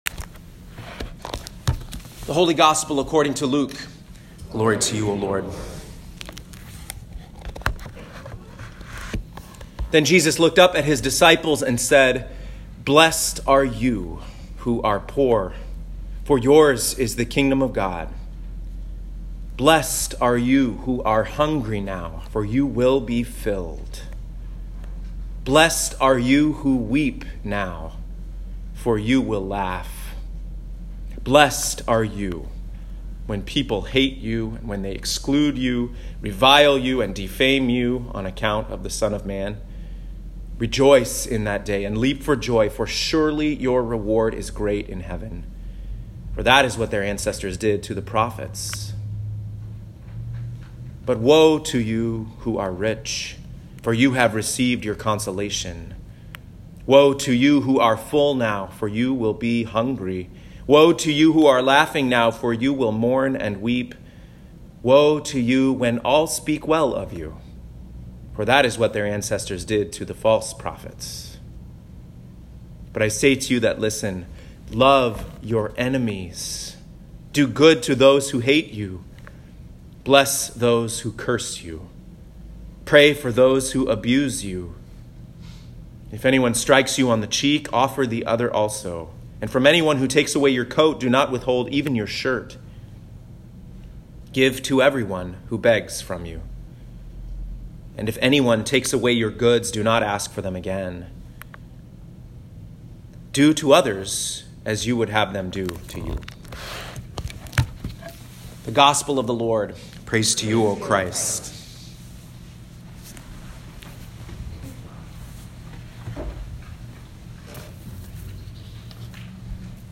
Home › Sermons › A Great Patchwork